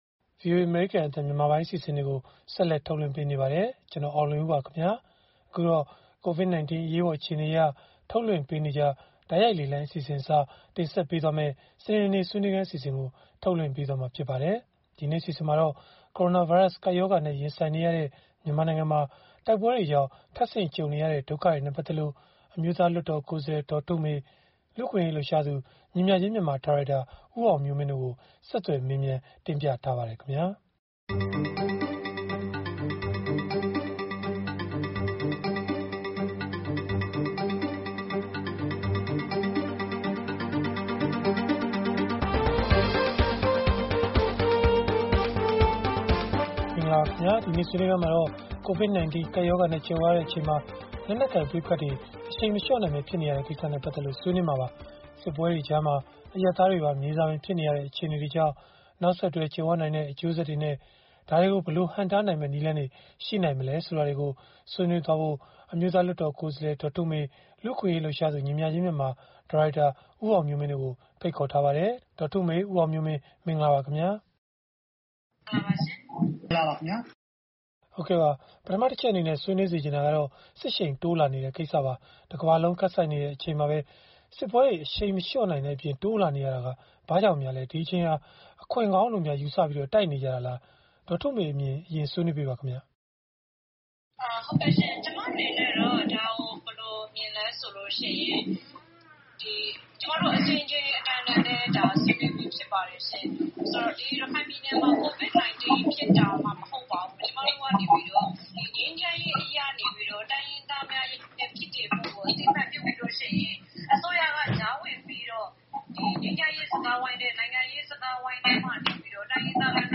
ဆက်သွယ်မေးမြန်းပြီး ဗွီအိုအေ စနေနေ့ဆွေးနွေးခန်း အစီအစဉ်မှာ တင်ဆက်ထားပါတယ်။